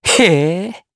Neraxis-Vox_Happy4_jp.wav